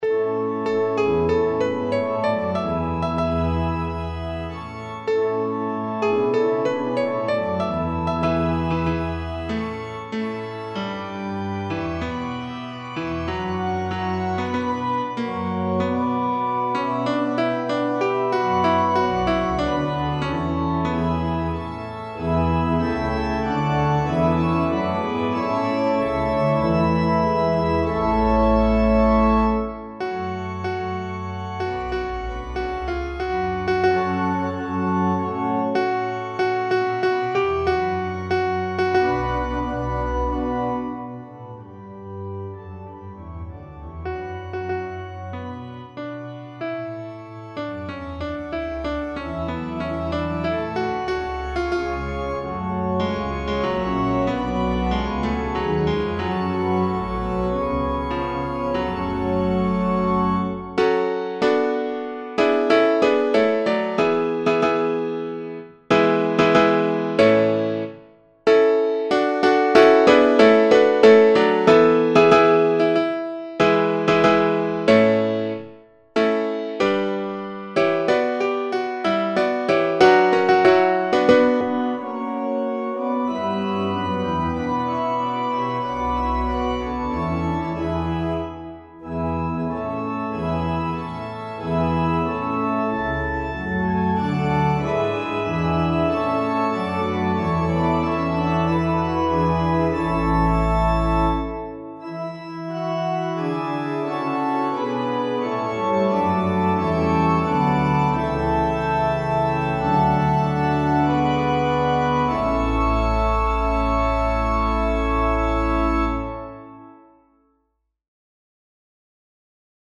is set for Double Soprano, Alto, Tenor and Choir.
• All parts played together on piano
• All parts played together on wind instruments and organ